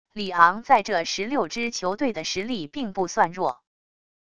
里昂在这十六支球队的实力并不算弱wav音频生成系统WAV Audio Player